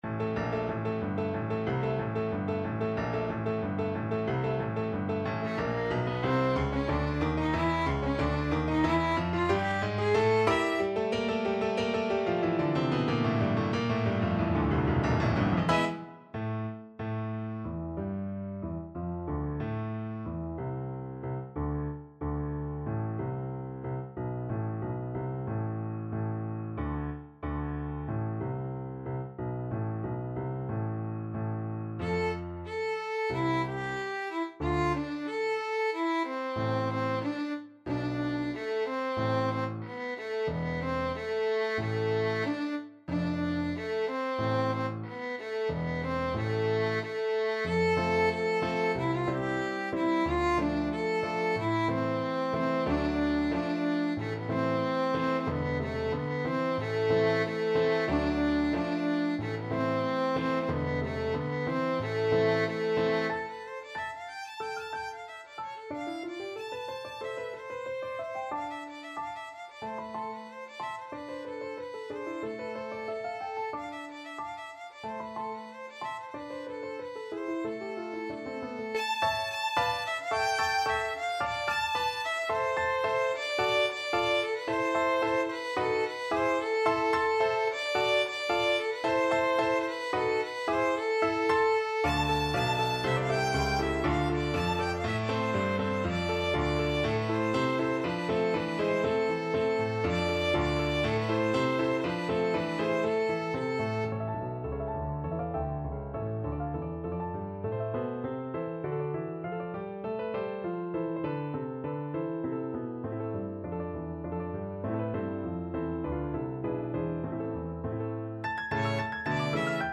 2/4 (View more 2/4 Music)
Allegro =92 (View more music marked Allegro)
Classical (View more Classical Violin Music)